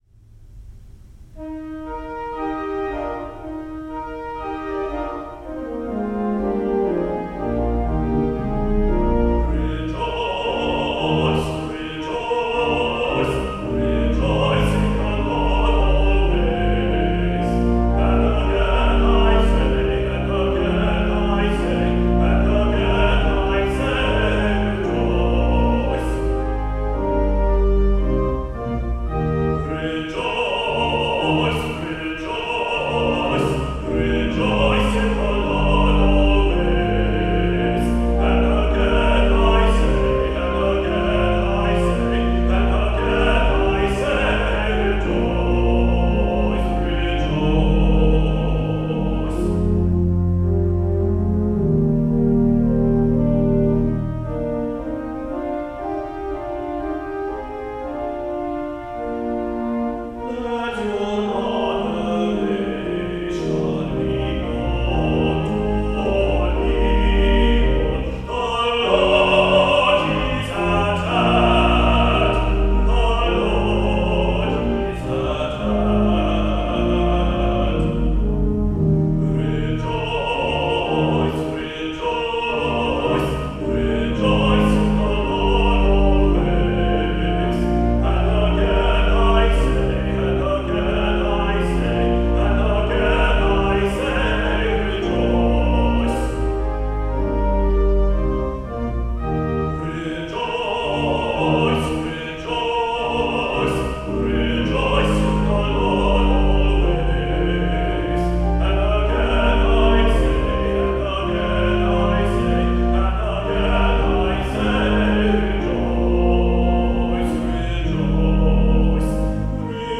Voicing: Medium Voice and Piano